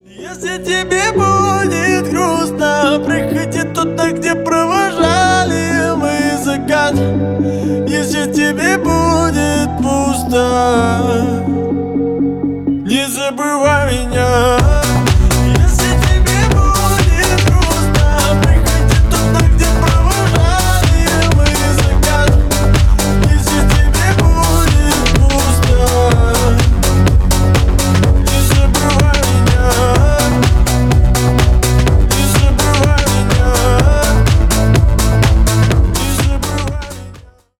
Ремикс # Поп Музыка
тихие